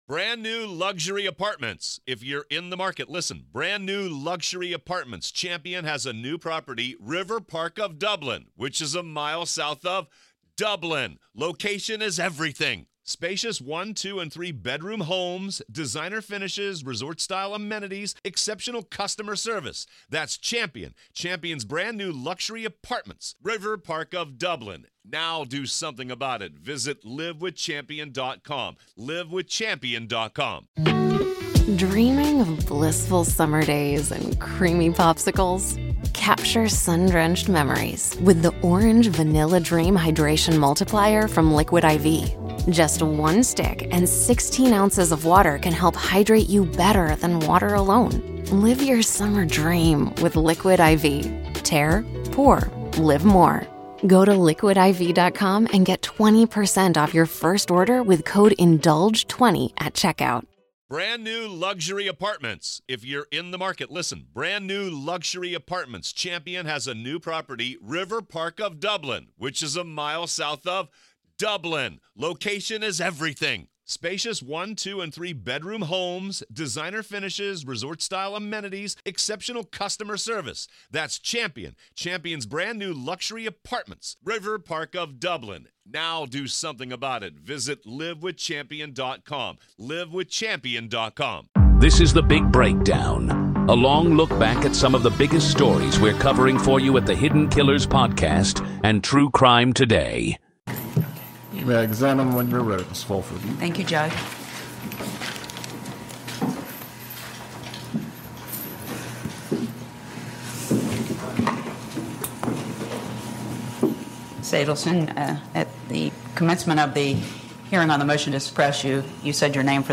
Her tone is calm, measured, even matter-of-fact, but the content paints a picture of someone deeply concerned with her comfort, not the case against her.
We give you the full testimony as it happened, without spin or commentary.